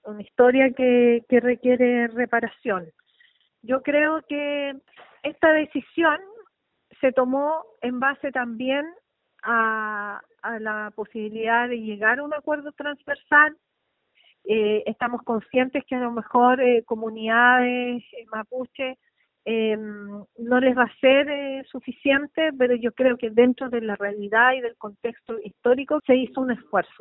La también militante socialista, Ana María Bravo, manifestó que probablemente habrá comunidades mapuche que no queden conformes.